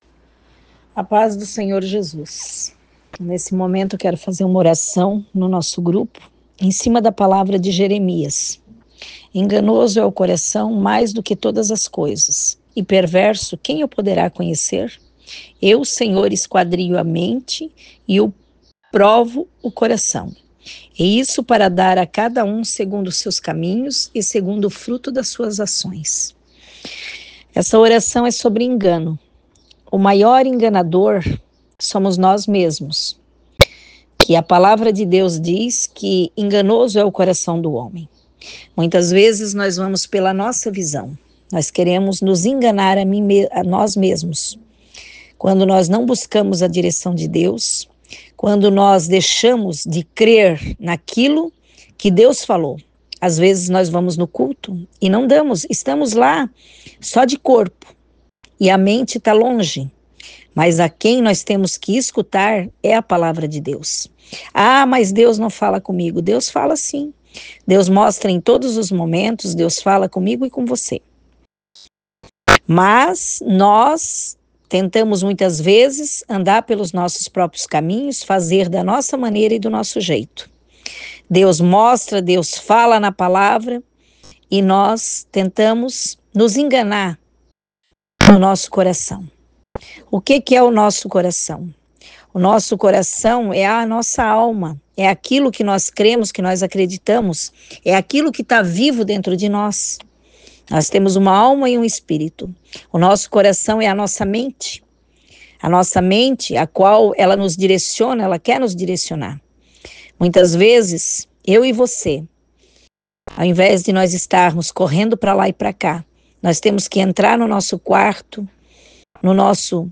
Oração – Enganoso é o Coração (Jeremias 17)